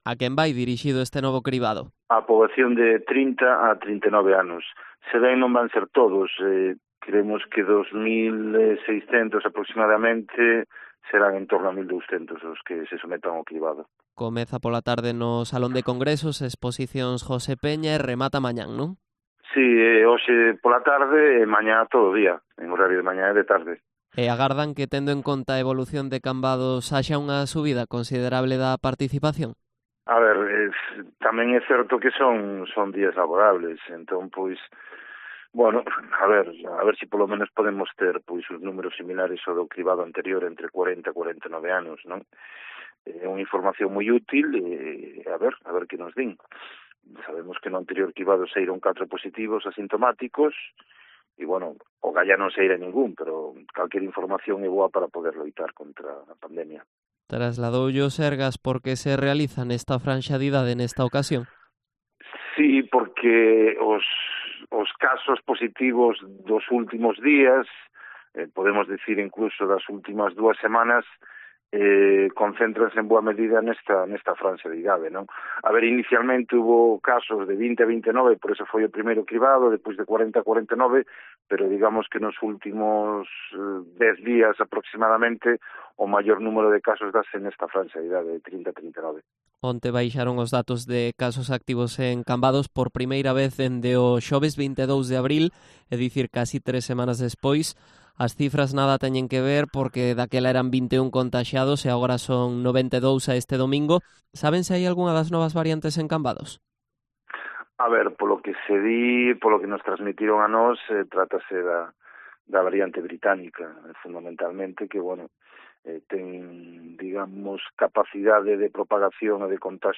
Entrevista a Tino Cordal, concelleiro de Sanidade de Cambados